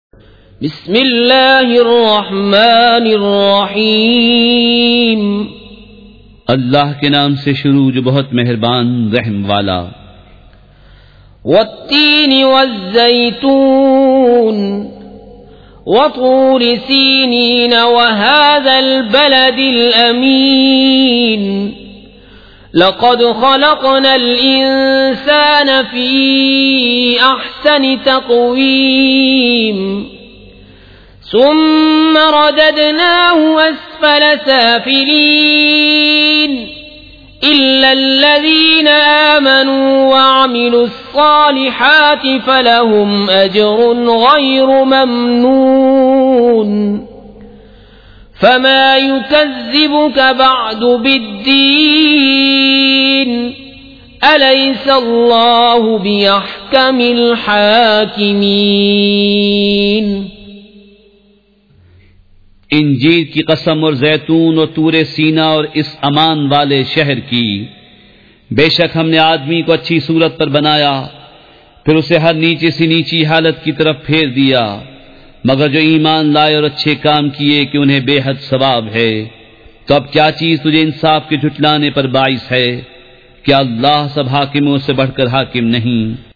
سورۃ التین مع ترجمہ کنزالایمان ZiaeTaiba Audio میڈیا کی معلومات نام سورۃ التین مع ترجمہ کنزالایمان موضوع تلاوت آواز دیگر زبان عربی کل نتائج 1470 قسم آڈیو ڈاؤن لوڈ MP 3 ڈاؤن لوڈ MP 4 متعلقہ تجویزوآراء